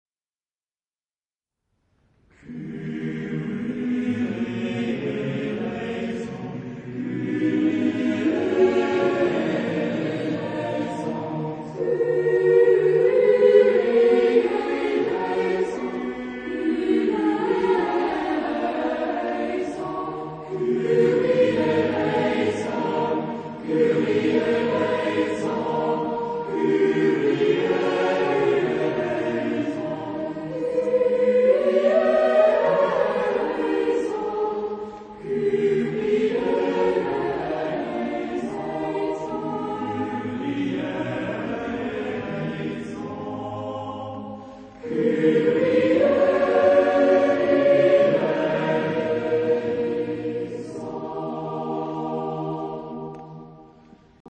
Epoque: 17th century  (1600-1649)
Genre-Style-Form: Motet ; Mass ; Sacred
Type of Choir: SATTB  (5 mixed OR boy choir voices )
Tonality: D minor
sung by Knabenchor Bremen
Discographic ref. : 7. Deutscher Chorwettbewerb 2006 Kiel